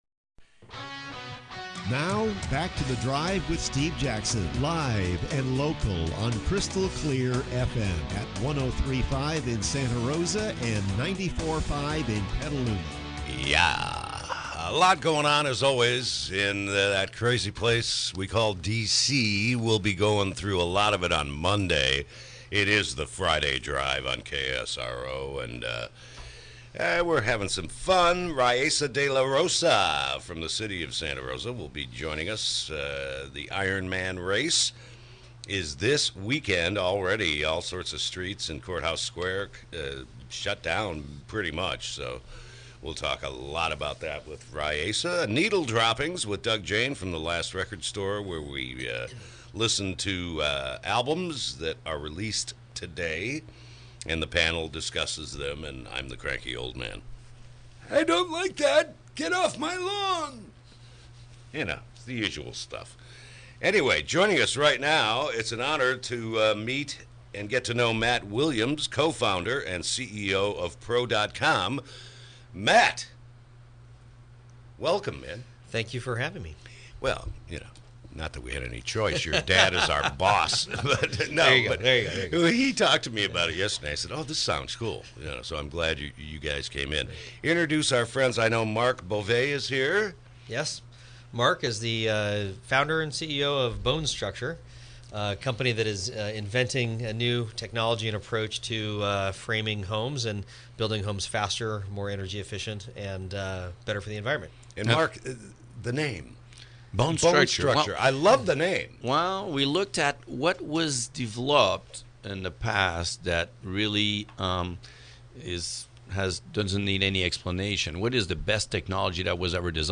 Interview: BONE Structure Homes bring new rebuild strategy to Sonoma County